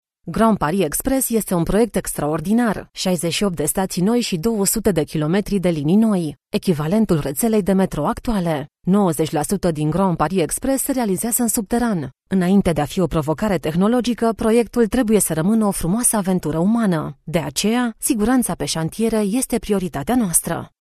Naturelle, Accessible, Fiable, Commerciale, Corporative
Vidéo explicative
Thanks to her extensive career in broadcasting, her voice has been trained for reliable authenticity and clarity.
Her professional home studio is fully connected for your live and directed sessions – alternatively she can self-direct to your brief.